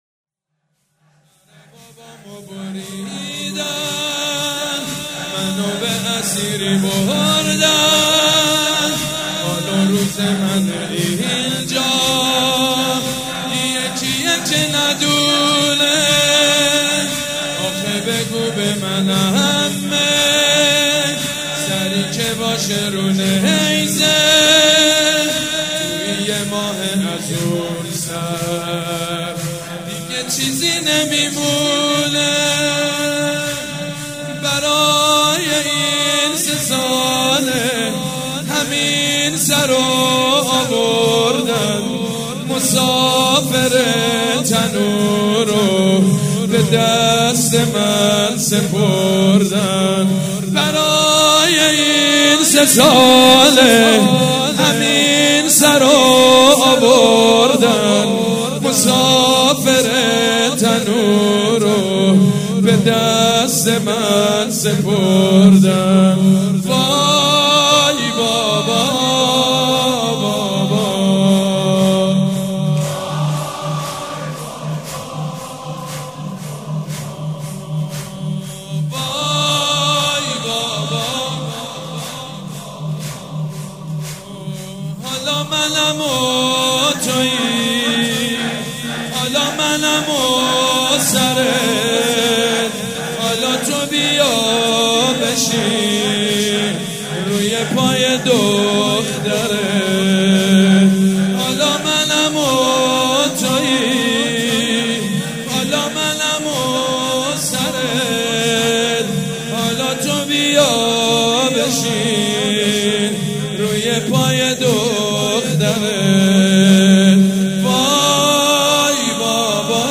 شب سوم محرم الحرام‌ سه شنبه ۱3 مهرماه ۱۳۹۵ هيئت ريحانة الحسين(س)
سبک اثــر زمینه مداح حاج سید مجید بنی فاطمه
مراسم عزاداری شب سوم